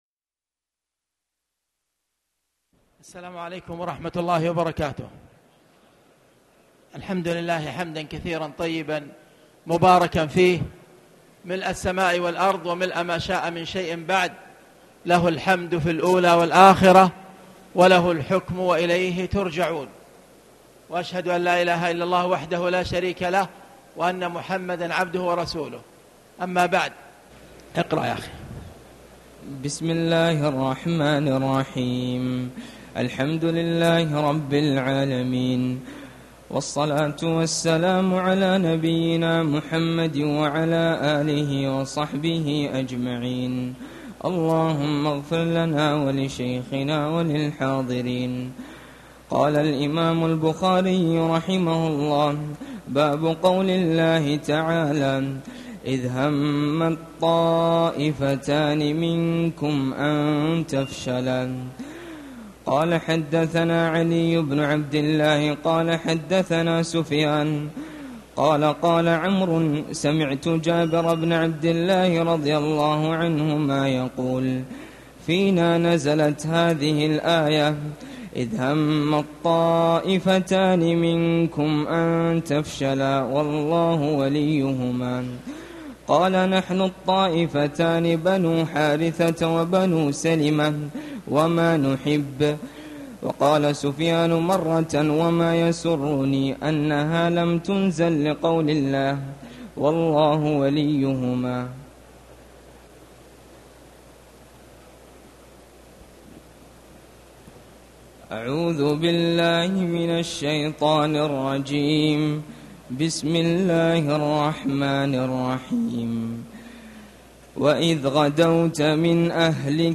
تاريخ النشر ١٨ رمضان ١٤٣٨ هـ المكان: المسجد الحرام الشيخ